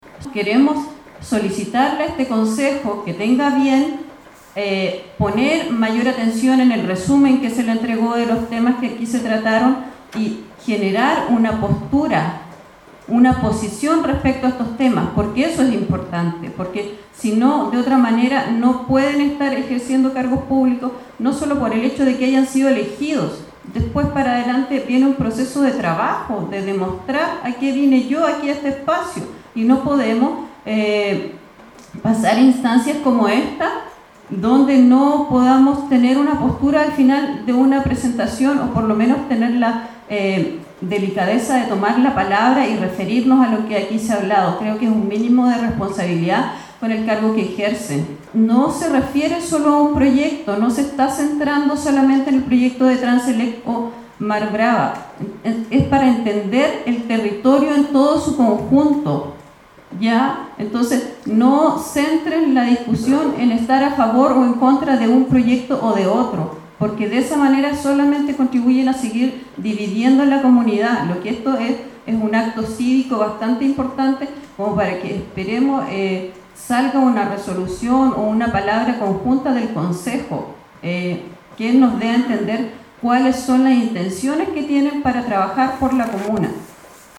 Más de un centenar de personas participó de esta audiencia pública realizada en Ancud en torno a las amenazas al territorio de Chiloé por los proyectos de energía eólica y carreteras eléctricas.